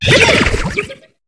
Cri de Vorastérie dans Pokémon HOME.